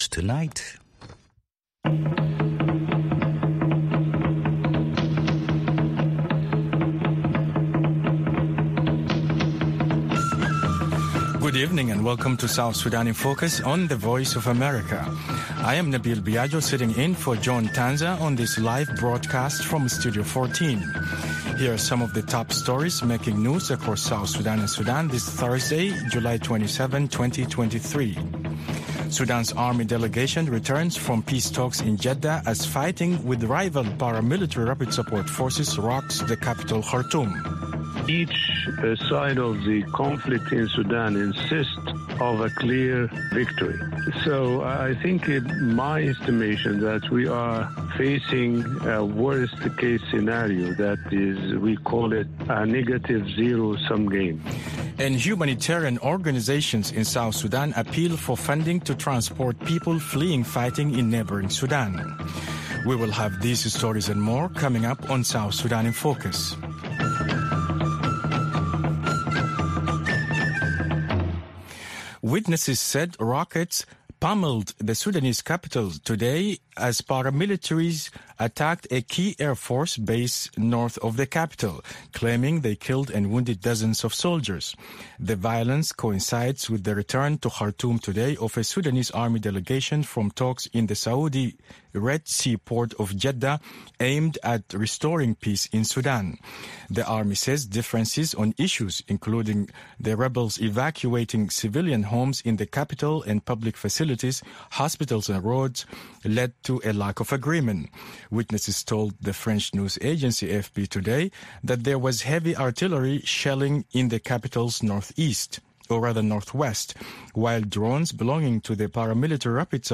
South Sudan in Focus airs at 7:30 pm in Juba (1630 UTC) and can be heard on FM stations throughout South Sudan, on shortwave, and on VOA’s 24-hour channel in Nairobi at 8:30 pm.